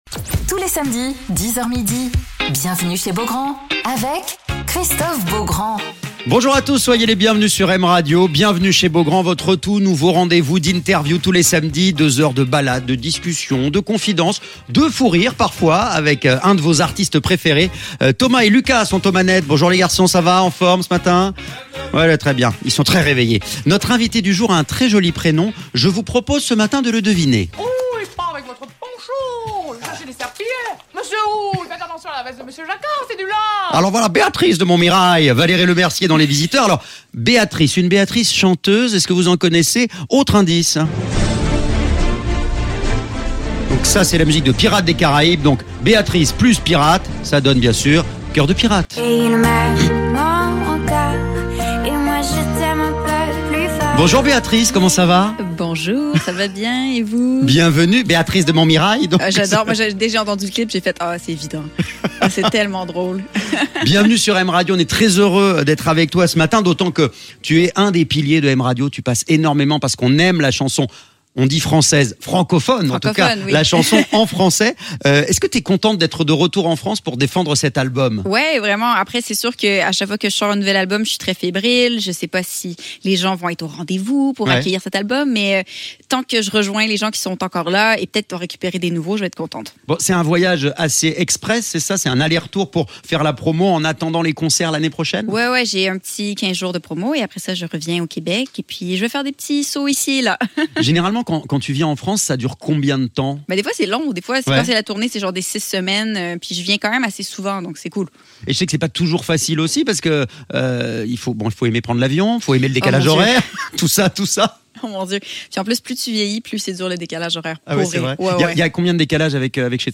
Alors qu'elle vient de sortir son album "cavale", Cœur De Pirate est l'invitée de Christophe Beaugrand sur M Radio